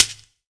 PERC - SHAKE DOWN.wav